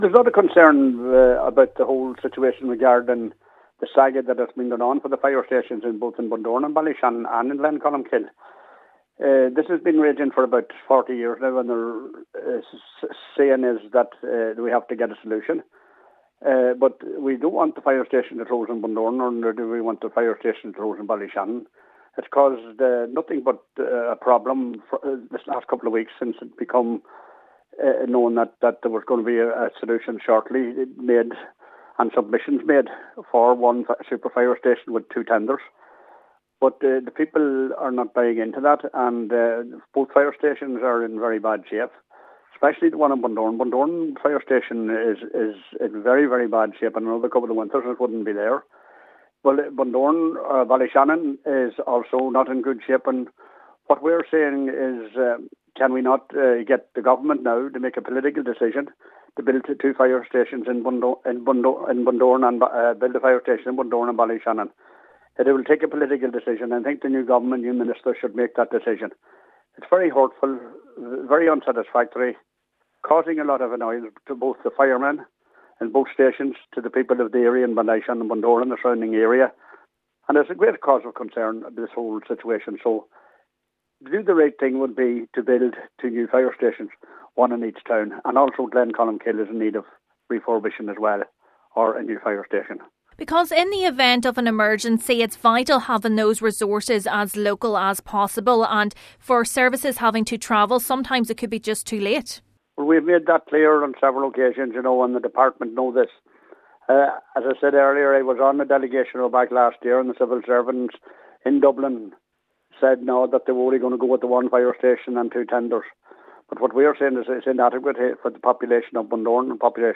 Councillor Michael McMahon says the saga has come to a stage where Government intervention is needed: